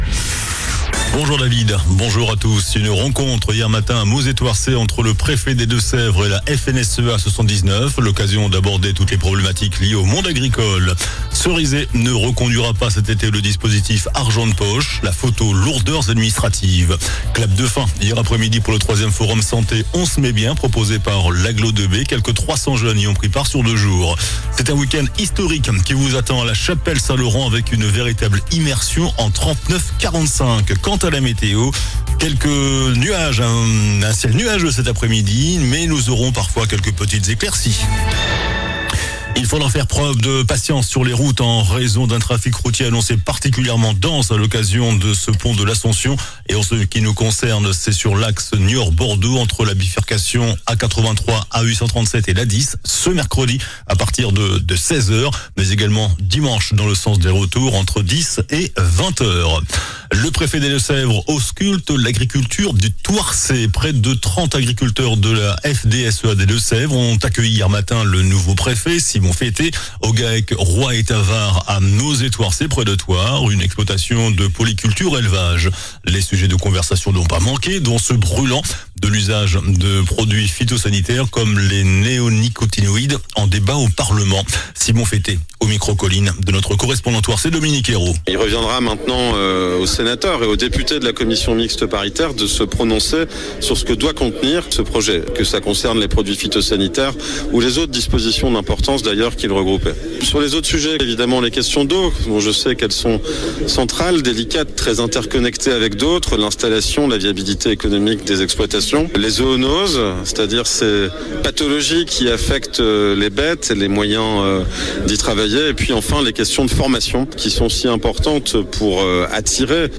JOURNAL DU MERCREDI 28 MAI ( MIDI )